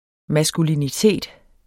Udtale [ masguliniˈteˀd ]